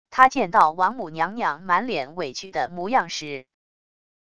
他见到王母娘娘满脸委屈的模样时wav音频生成系统WAV Audio Player